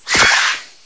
The cries from Chespin to Calyrex are now inserted as compressed cries